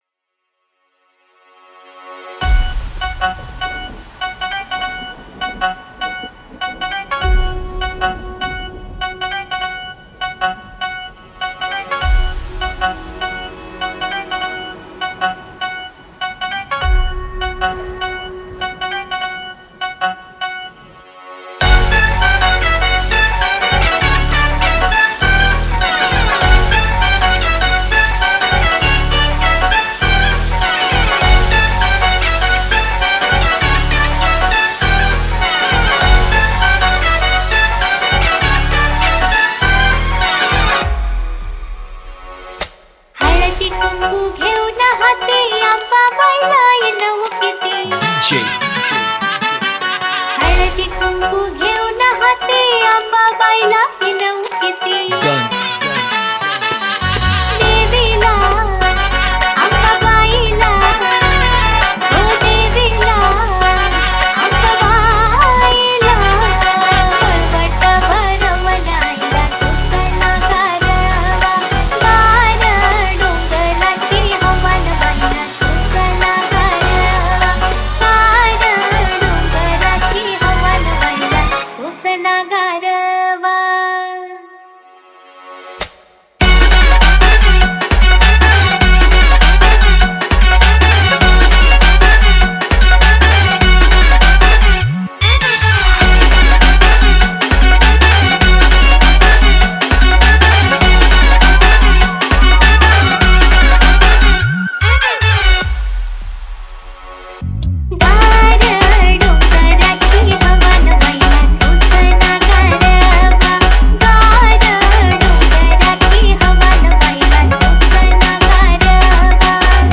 • Category: MARATHI SOUNDCHECK